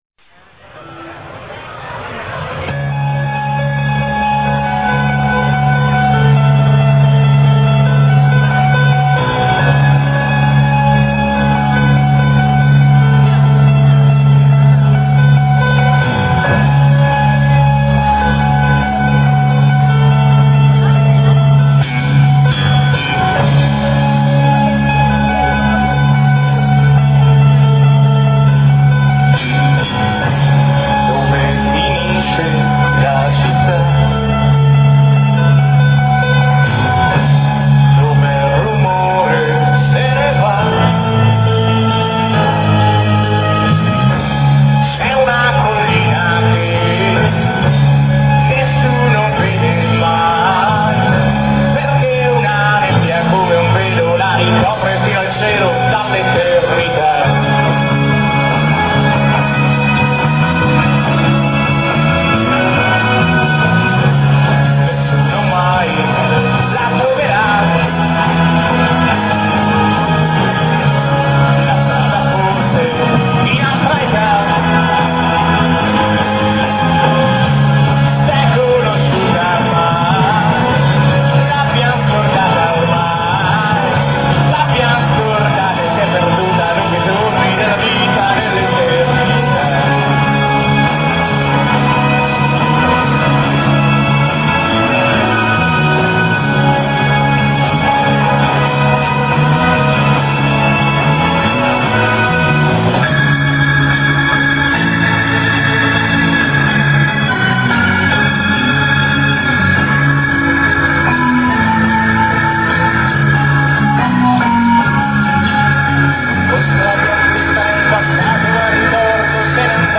Cover Band
Parè (TV), 1997